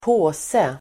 Uttal: [²p'å:se]